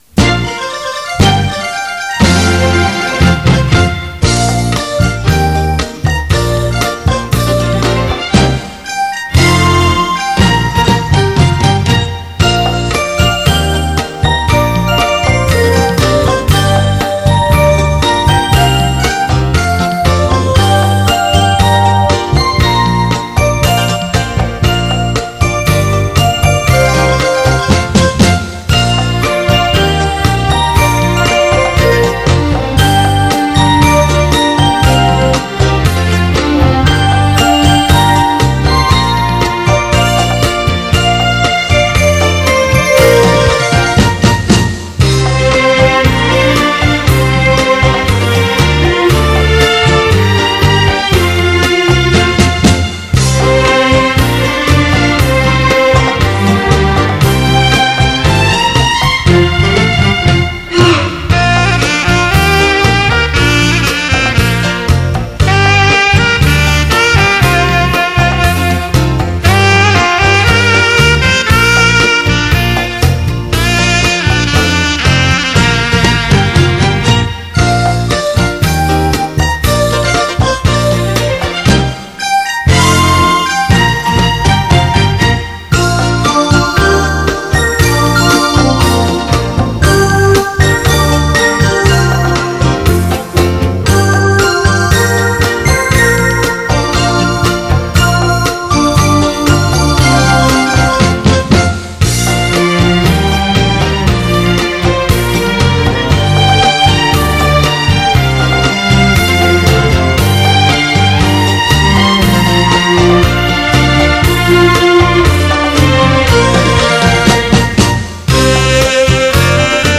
（恰恰）
华丽的配器 精巧的编排
跳动的灵巧
与活灵活现的花边音
小装饰鼓点
热辣风格 奔放节奏
键盘
萨克斯
大提琴 中提琴
小提琴